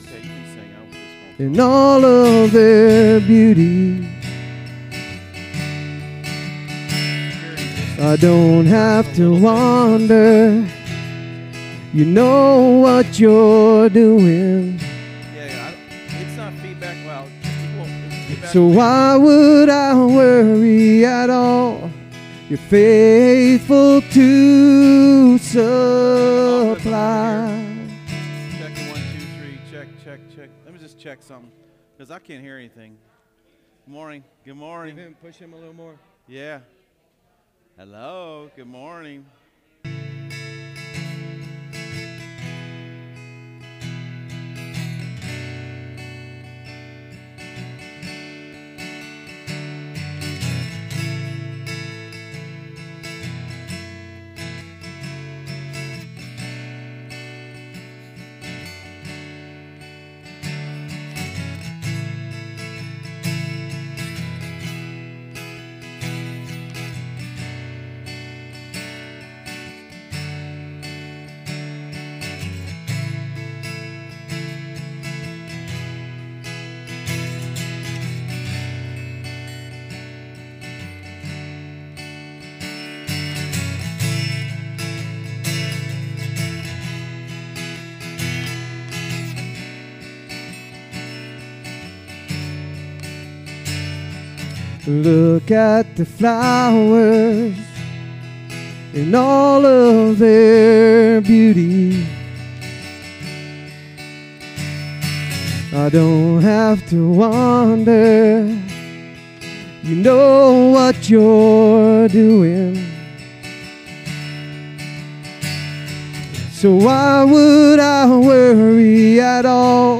SERMON DESCRIPTION Every moment of God has leaders.